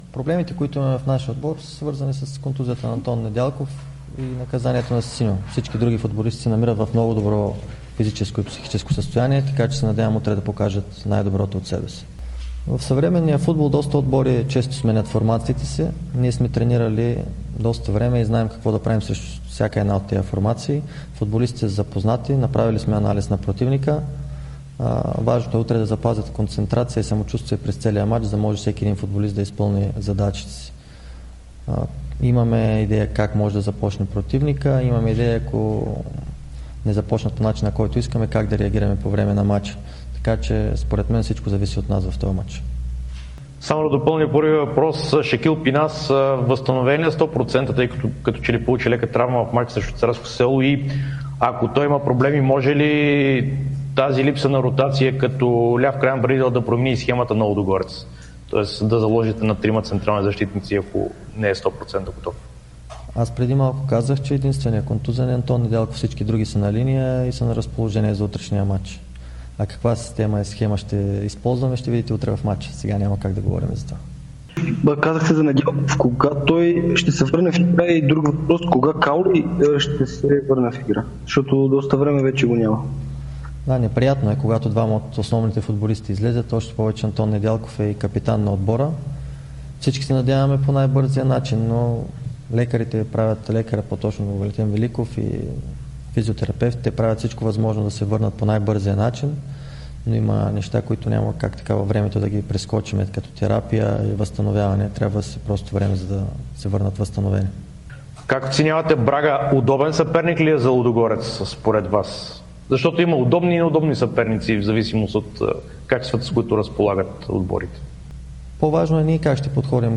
Това стана на официалната пресконференция преди двубоя.